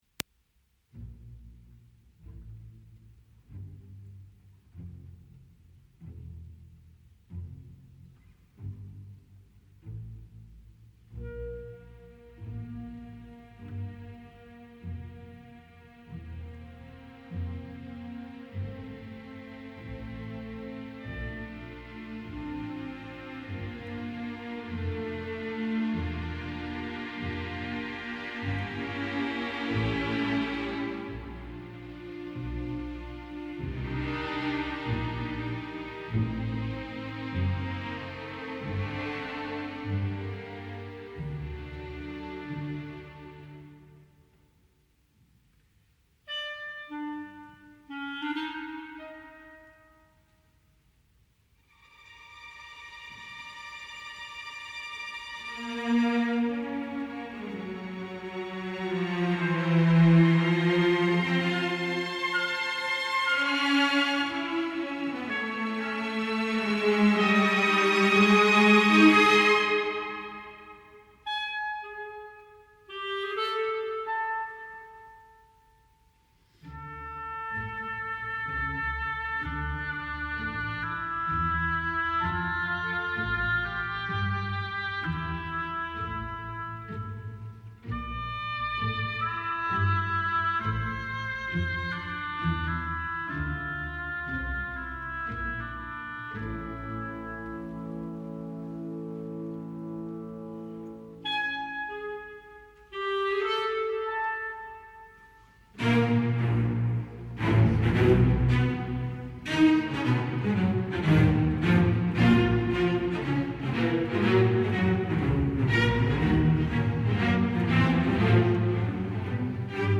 Hij herhaalt een aantal van de thema’s uit de eerdere delen, maar nu bewust heel versnipperd, net als bij Beethoven.
Dan gaat hij een van die thema’s uitwerken tot een fuga net als bij Beethoven, en, jawel, met een hectisch gepuncteerd ritme!